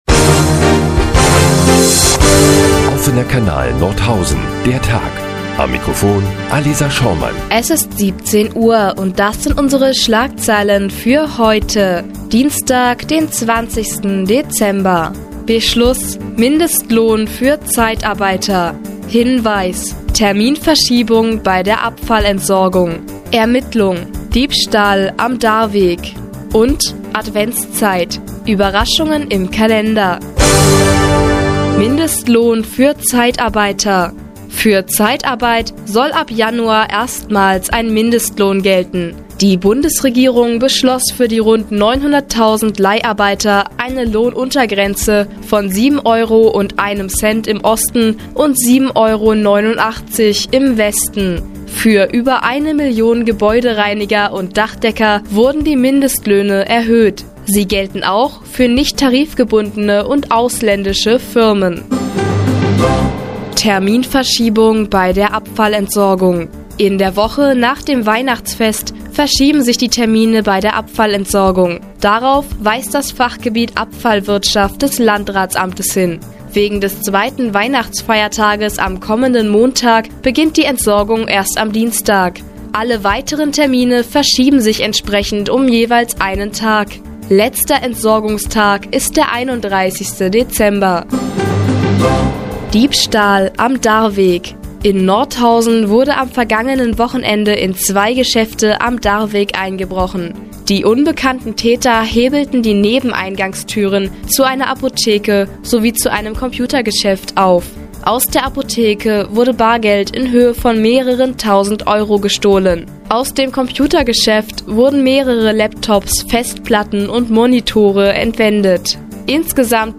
20.12.2011, 17:00 Uhr : Seit Jahren kooperieren die nnz und der Offene Kanal Nordhausen. Die tägliche Nachrichtensendung des OKN ist nun auch in der nnz zu hören.